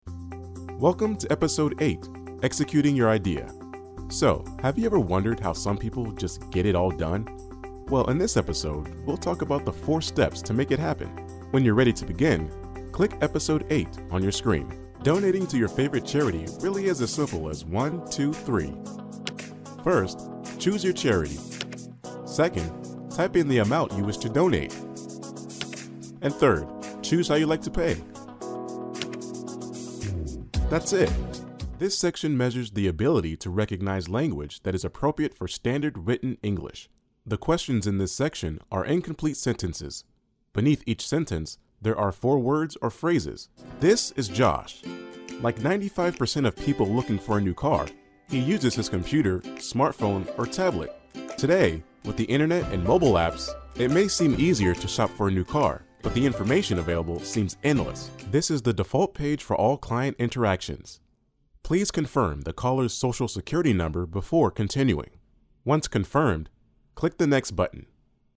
Engels (Amerikaans)
Diep, Stedelijk, Vriendelijk
Explainer